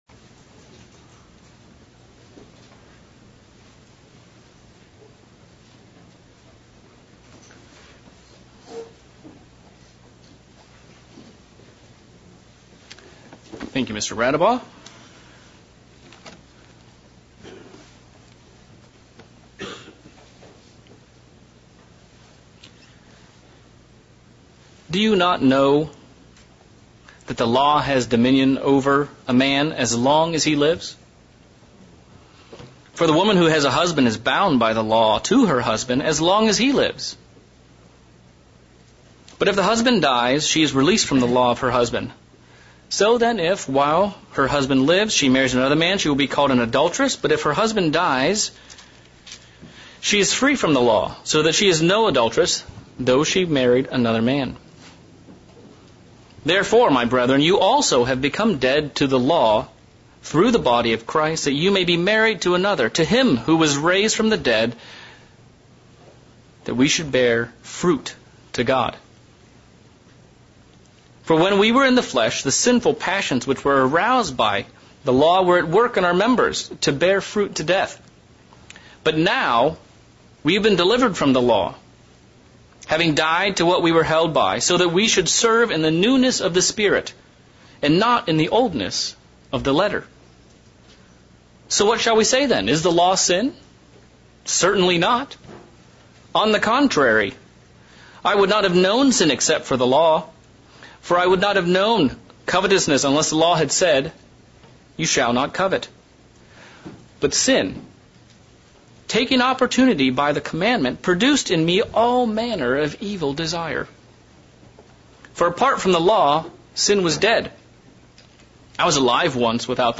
Passover Preparation sermon looking at Passover preparation from Paul's point of view. What lessons can we learn from Paul's approach to Passover?